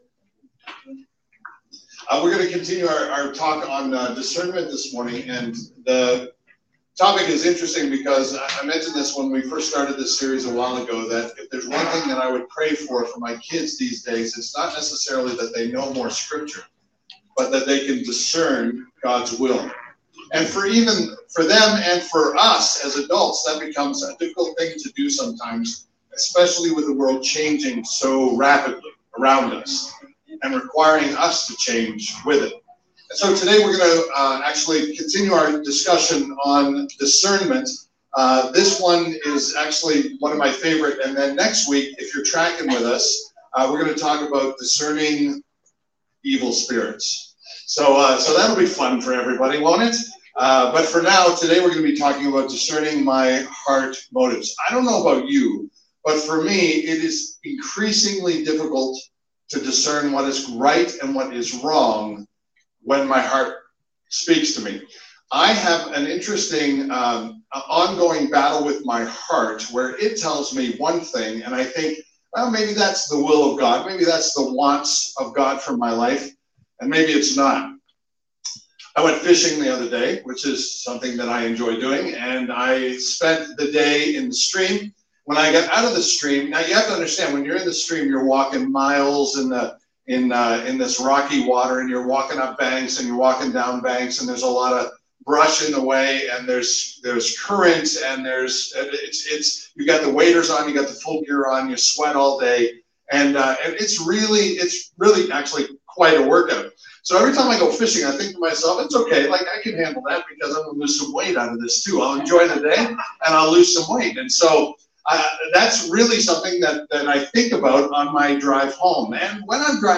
This sermon covers the big question; how do I discern my heart's motives? We dive deep into the meaning behind Hebrews 4:12-13.